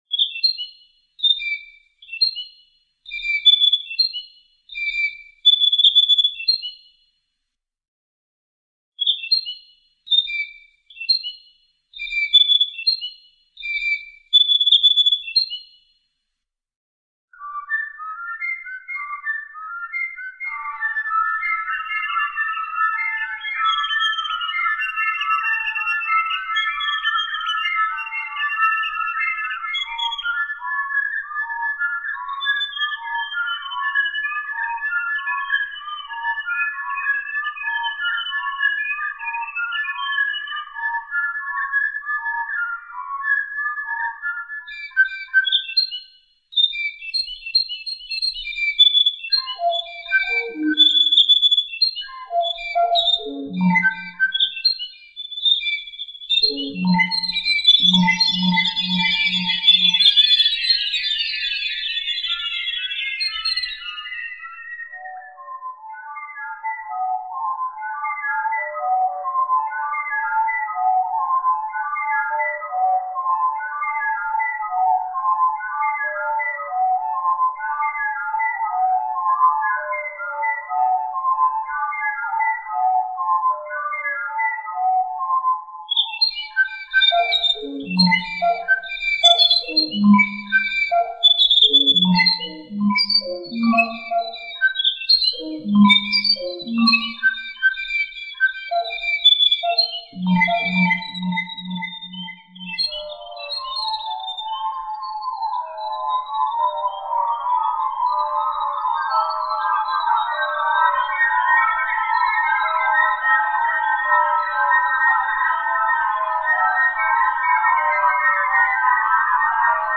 Le résultat est parfois proche d'un collage qui aurait une métrique (une mesure, un tempo); parfois, je transforme à l'aide de machines (synthétiseur, échantillonneur, effets,...) ces sons animaux en sons musicaux humains qui s'apparentent alors à nos familles d'instruments (percussions, instruments mélodiques,...) couvrant toutes les fréquences du grave à l'aigu.
ces musiques sont garanties sans ajout d'instruments; en outre, les animaux n'ont pas été maltraités à l'enregistrement!
une fauvette de Nouvelle-Zélande